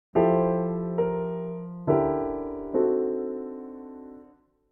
ディミニッシュ・アプローチ
c♯-e-g-b♭というハーモナイズ
特に今回は、dim7の各音が全て半音でD7(-9)に繋がるので、連結もスムーズです。